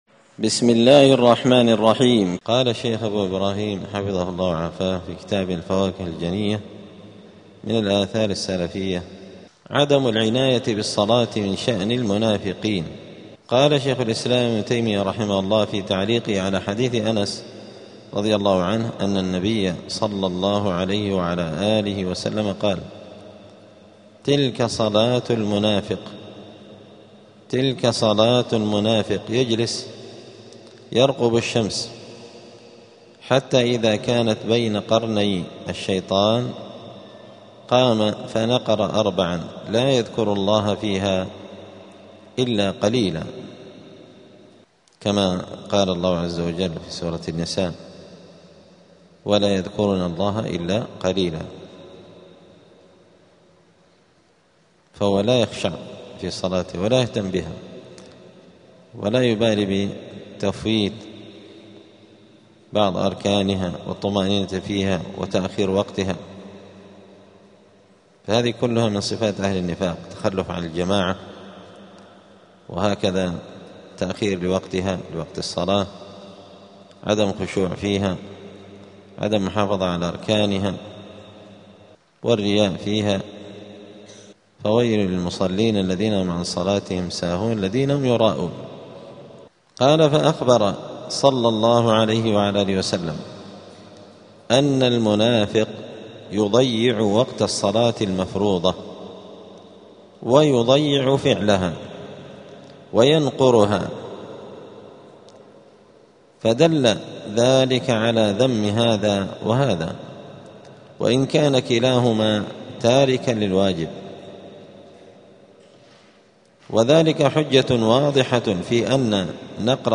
دار الحديث السلفية بمسجد الفرقان بقشن المهرة اليمن
*الدرس الواحد والتسعون (91) {عدم العناية بالصلاة من شأن المنافقين}.*